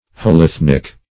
Search Result for " holethnic" : The Collaborative International Dictionary of English v.0.48: Holethnic \Hol*eth"nic\, a. Of or pertaining to a holethnos or parent race.